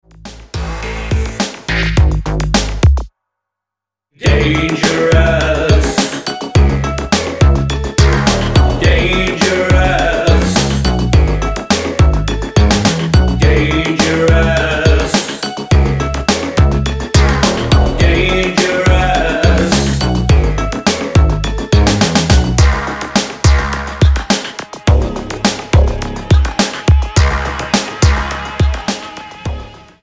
Tonart:C#m mit Chor